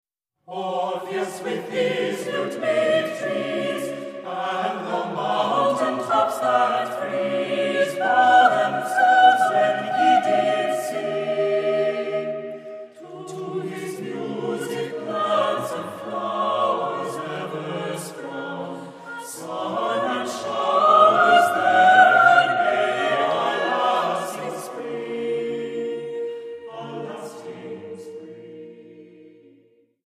Accompaniment:      None
Music Category:      Choral